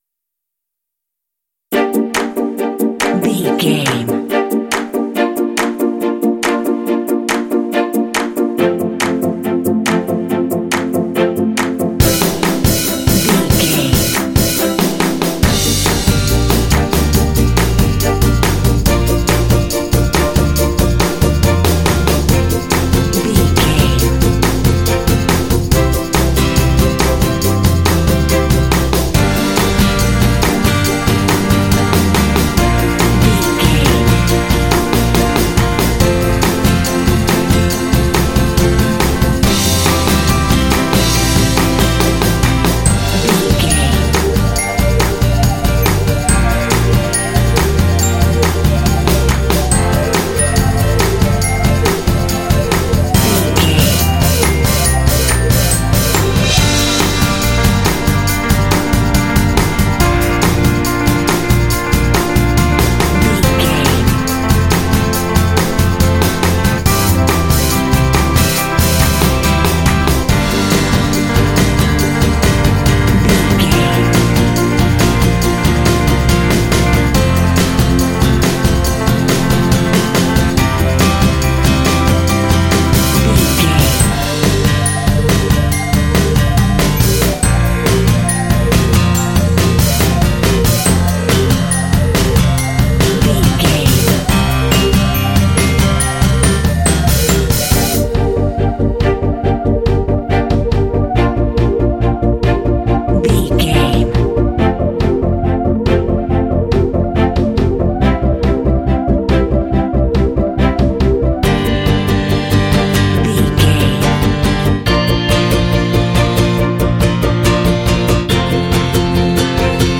Fast paced
In-crescendo
Dorian
Fast
energetic
strings
acoustic guitar
bass guitar
drums
synthesiser
symphonic rock
cinematic
alternative rock